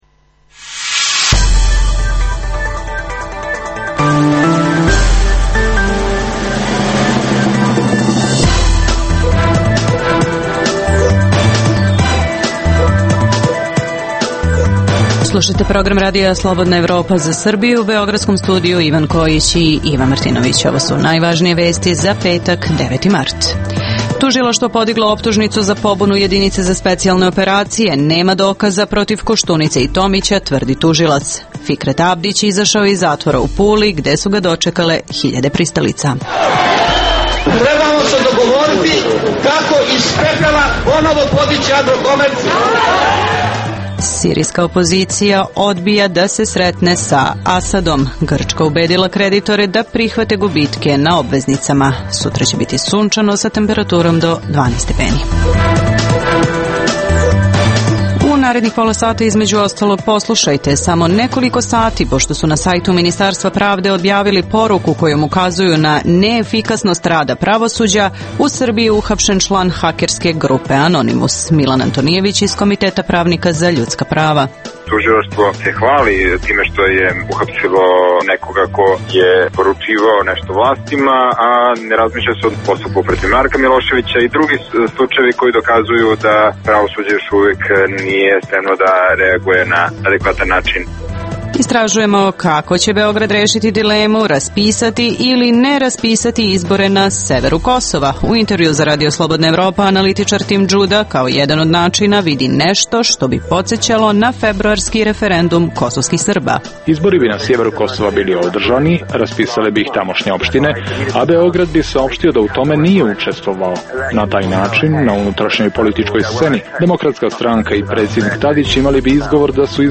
- Kako će Beograd rešiti dilemu raspisati ili ne izbore na severu Kosova. U intervjuu za RSE o tome govori analitičar Tim Judah.